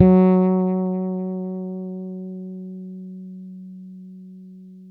-MM BRYF F#4.wav